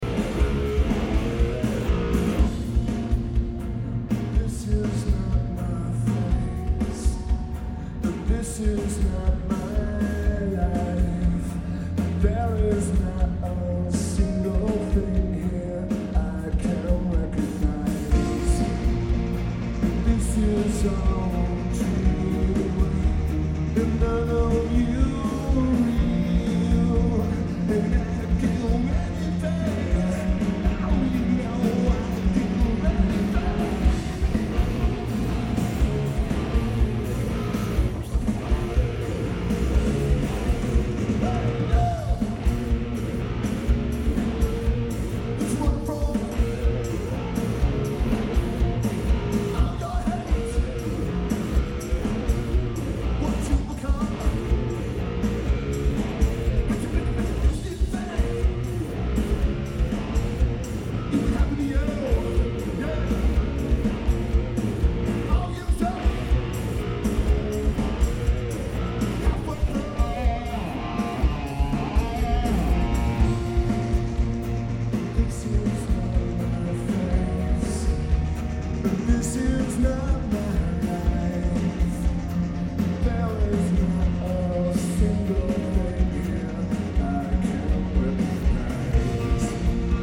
Verizon Wireless Music Center